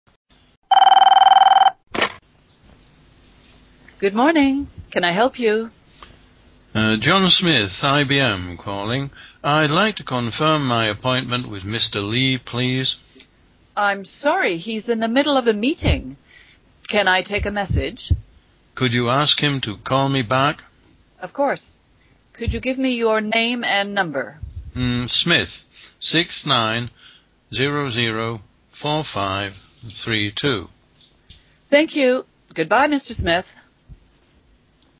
Telephone dialogue 11 sound/dlg_11.MP3